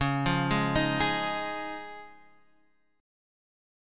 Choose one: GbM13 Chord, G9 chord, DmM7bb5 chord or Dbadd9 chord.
Dbadd9 chord